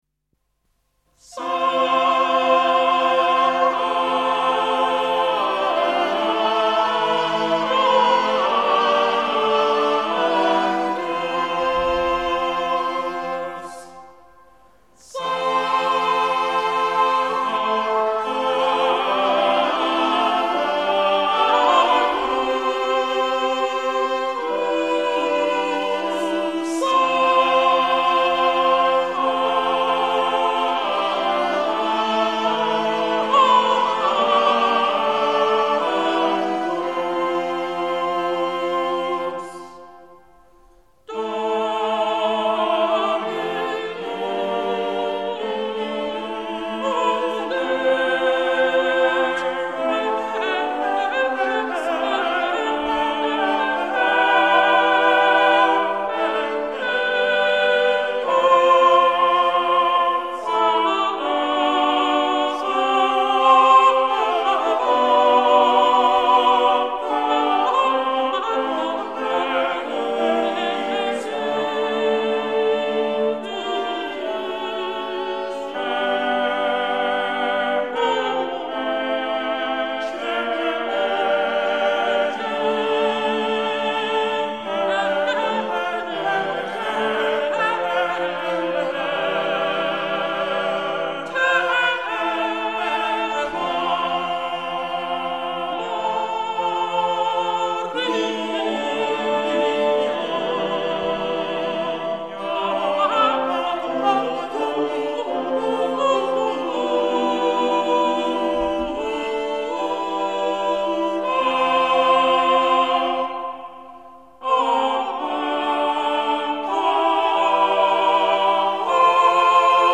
Scritta alla metà del secolo (tra il 1349 e il 1363) la Messa di Notre Dame si distingue dalle altre messe contemporanee per l'organico a 4 voci e per il trattamento isoritmico della melodia gregoriana nel Kyrie, Sanctus, Agnus e Ite missa est.
Deller consort, London | © 1961 | info